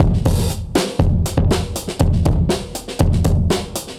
Index of /musicradar/dusty-funk-samples/Beats/120bpm/Alt Sound
DF_BeatA[dustier]_120-03.wav